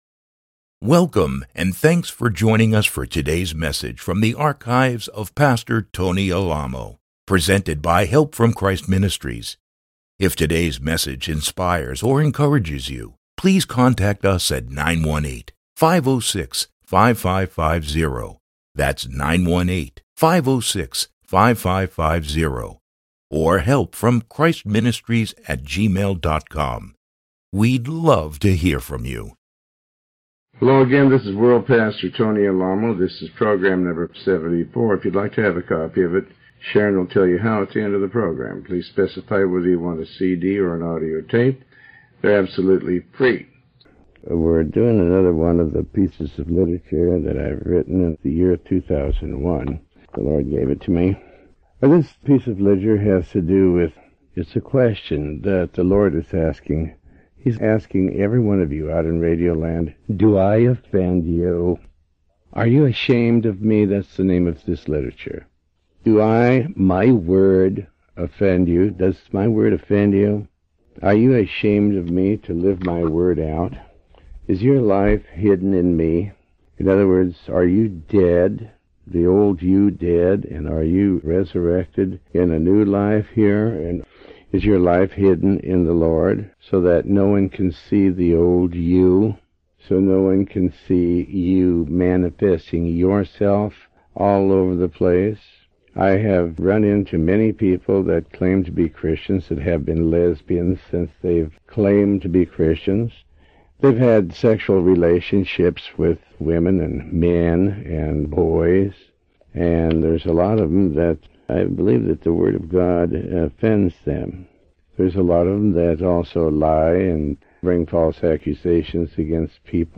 Sermon 74B